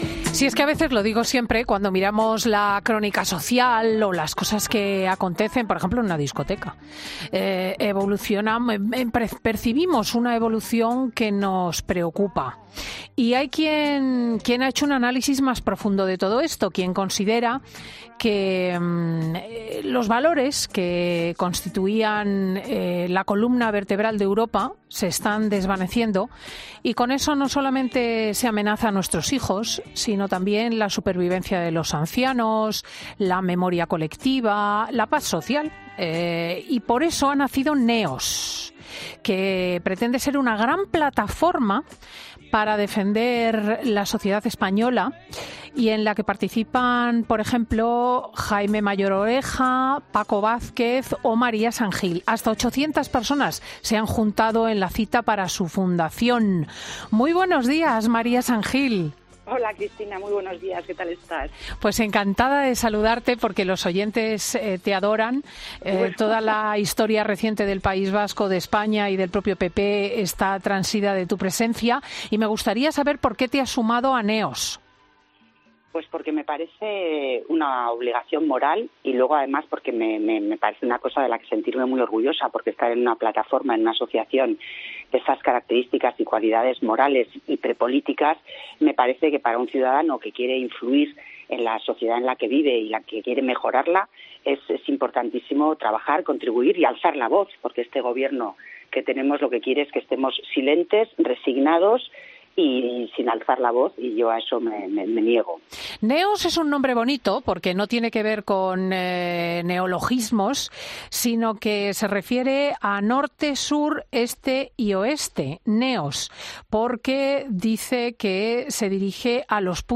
La ex presidenta del PP vasco lo explicaba en los micrófonos de Fin de Semana este sábado.